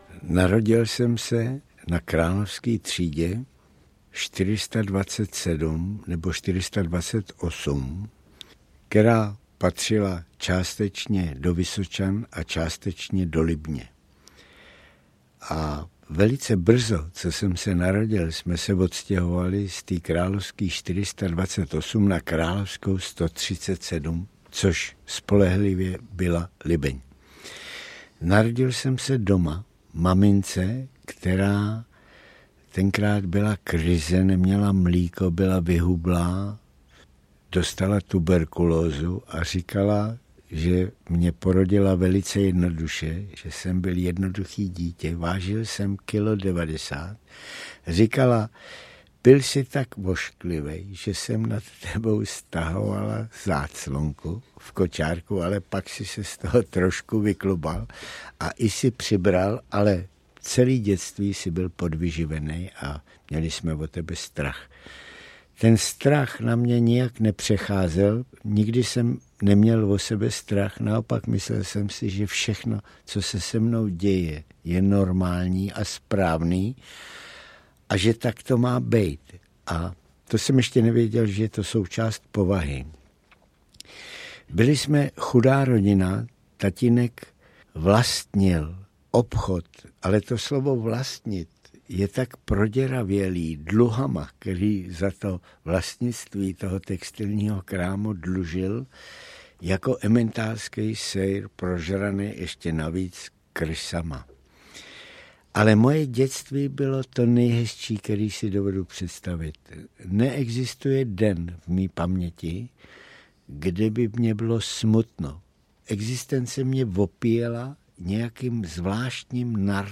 Audiokniha Arnošt Lustig - Osudy - obsahuje vzpomínkové vyprávění Arnošta Lustiga.
Ukázka z knihy